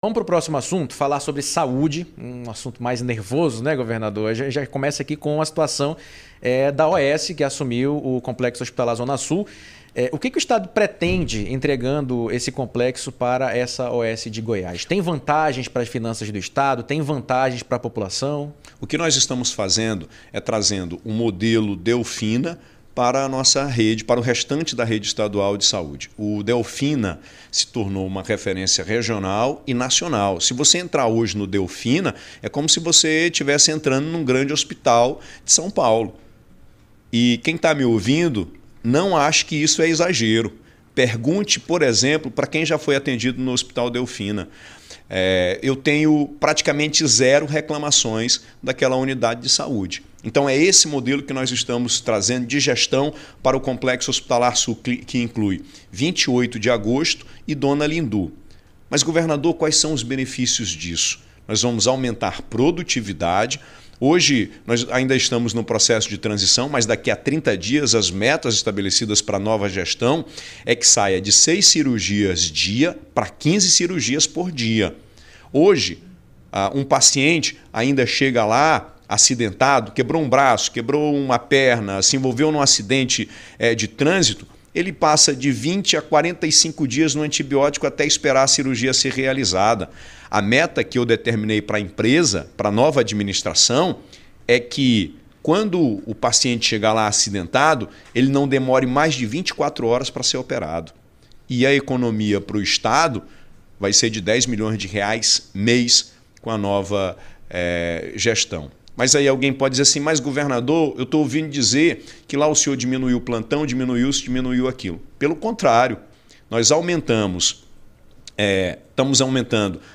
Governador falou sobre o assunto durante entrevista para o CBN Jornal da Manhã, nesta quinta-feira (19).
ENTREVISTA-WILSON-LIMA_SADE.mp3